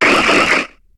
Cri de Vibraninf dans Pokémon HOME.